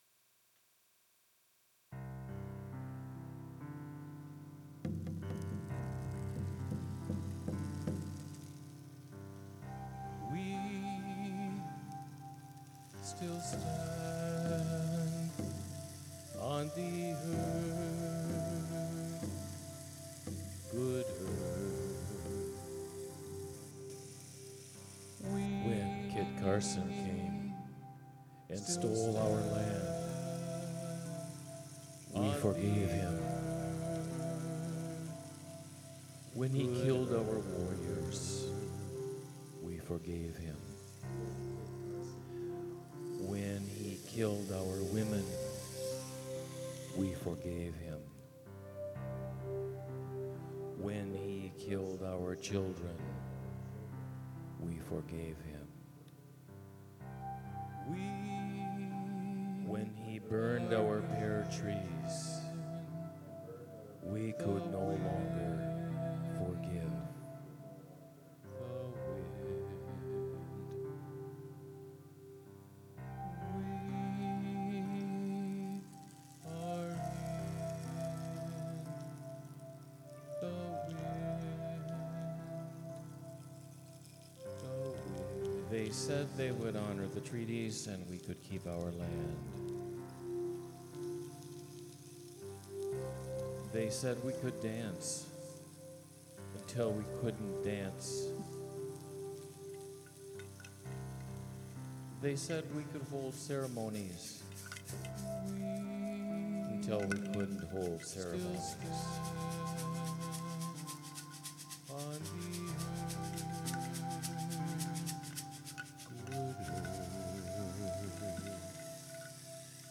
“We Still Stand” spoken word music for Indigenous resiliance and tenacity
keyboard